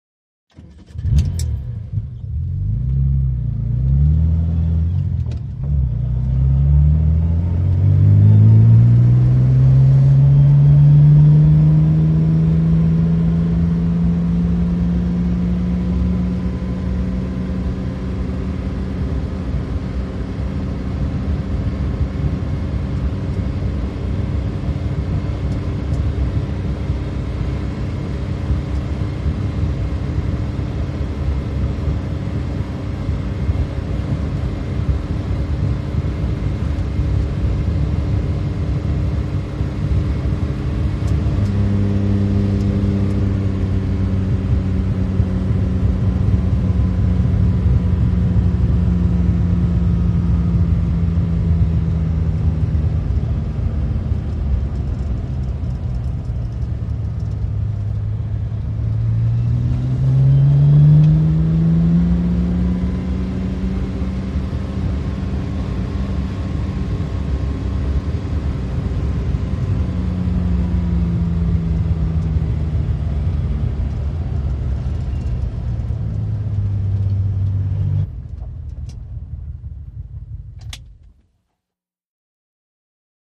VEHICLES ASTON MARTIN: INT: Constant run in one gear, switch off.